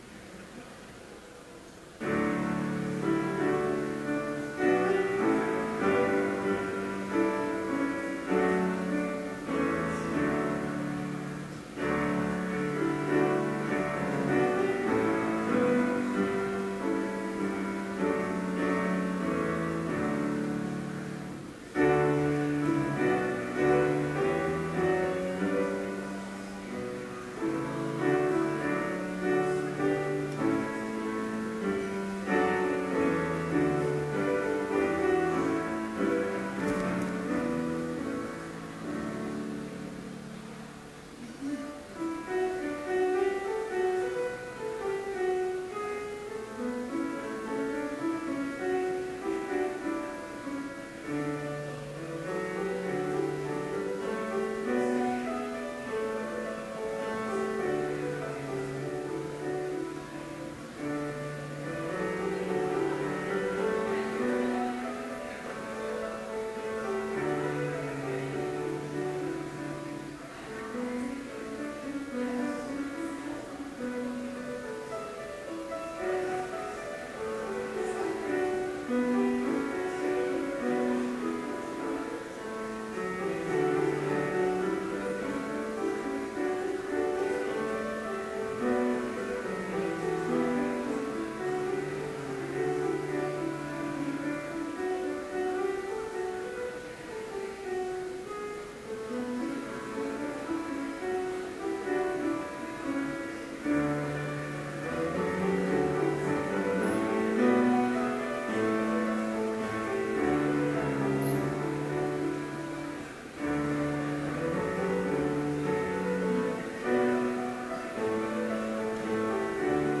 Complete service audio for Chapel - January 18, 2013